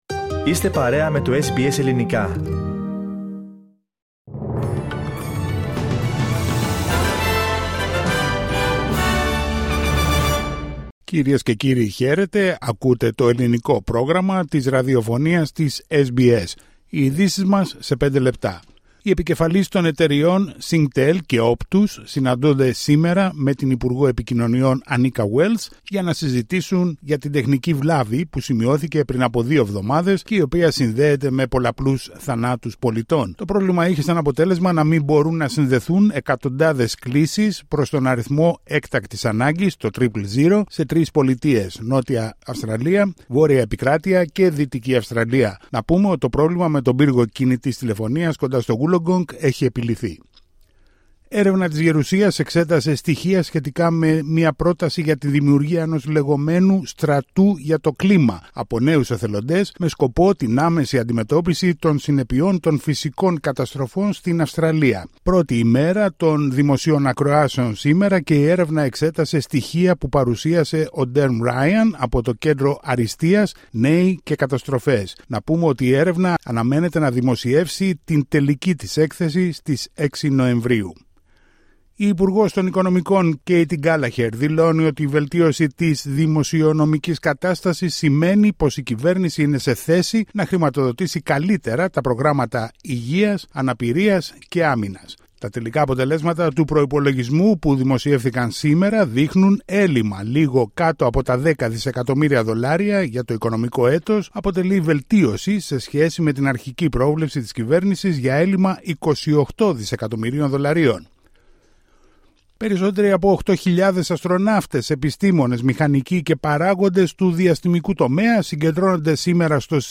Ειδήσεις σε 5' Δευτέρα 29 Σεπτεμβρίου